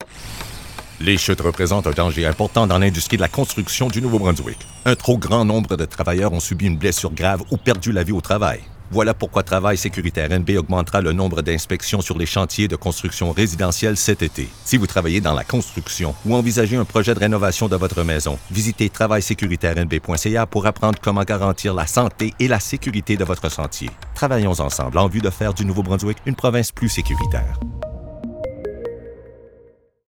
Campagne de protection contre les chutes – Annonce à la radio
campagne-de-protection-contre-les-chutes-annonce-à-la-radio.mp3